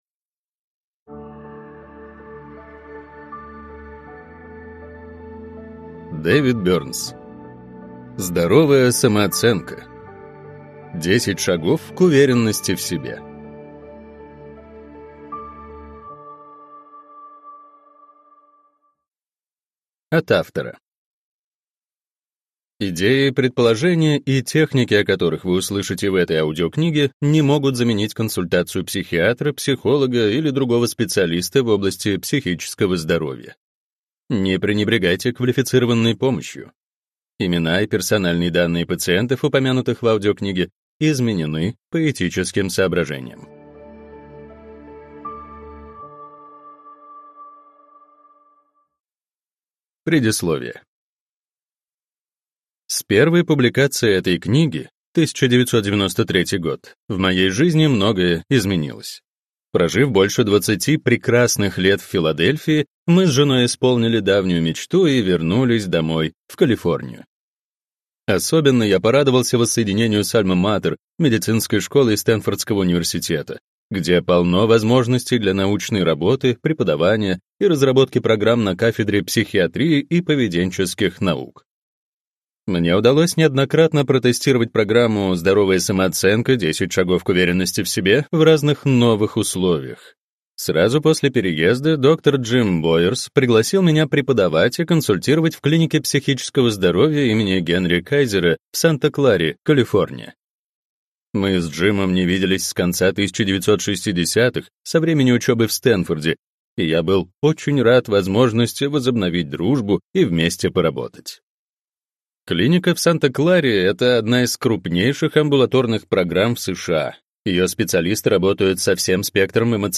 Аудиокнига Здоровая самооценка: 10 шагов к уверенности в себе | Библиотека аудиокниг
Прослушать и бесплатно скачать фрагмент аудиокниги